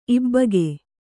♪ ibbage